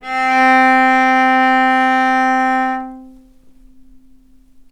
vc-C4-mf.AIF